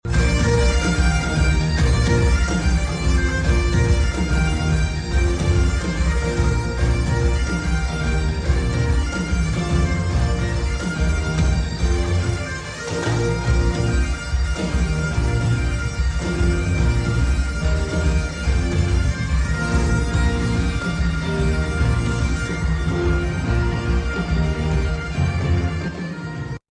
a blend of traditional music and pop and rock covers